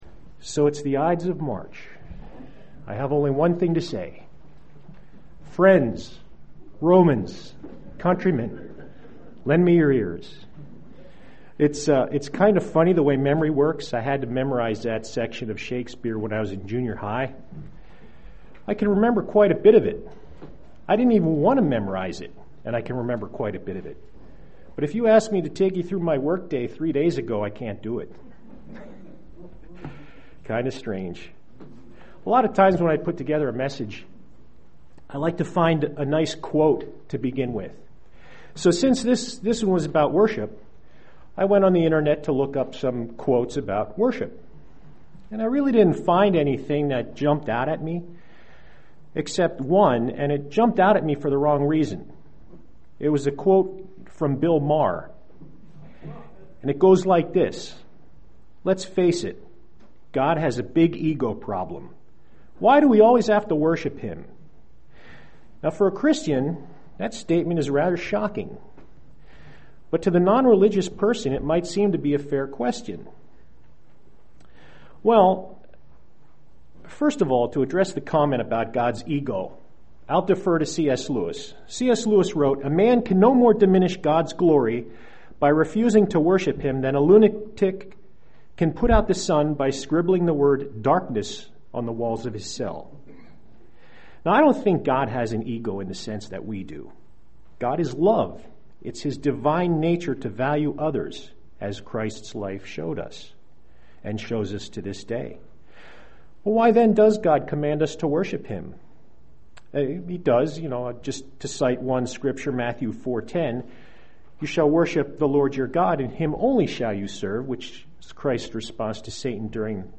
UCG Sermon Studying the bible?
Given in Lehigh Valley, PA